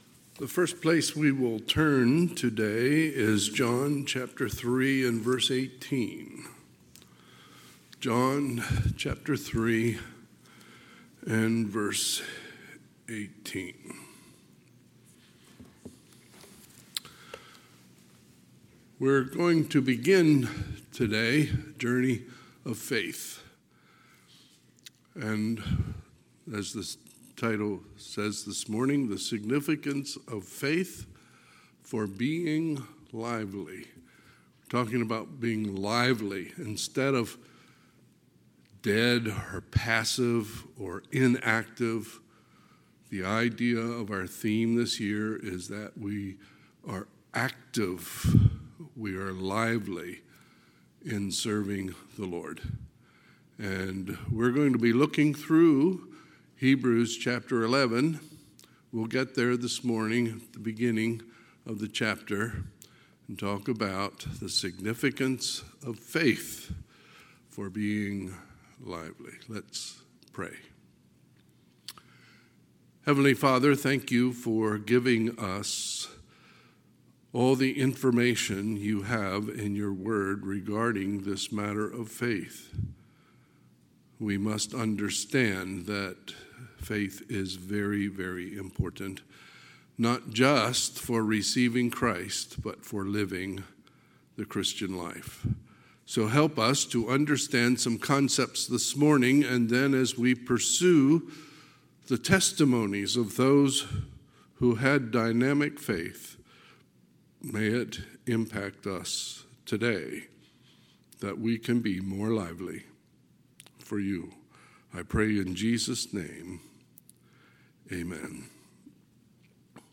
Sunday, August 11, 2024 – Sunday AM